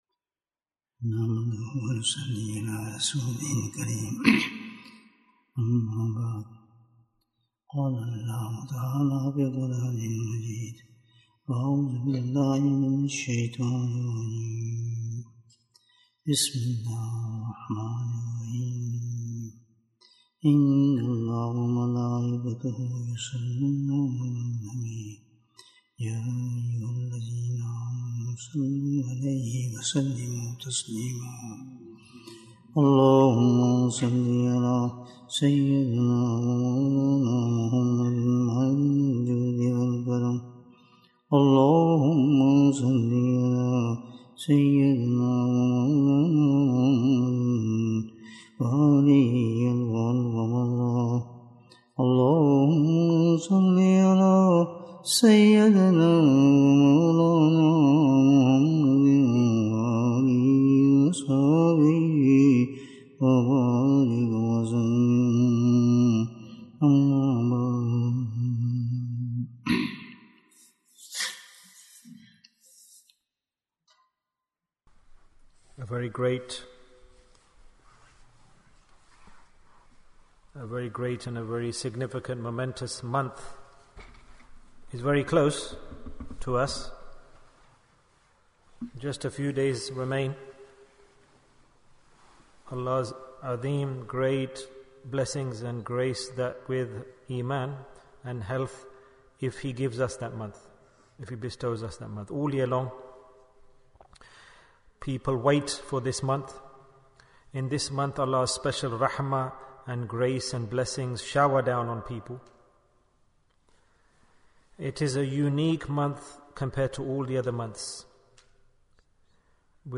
What is the Preparation for Ramadhan? Bayan, 79 minutes16th March, 2023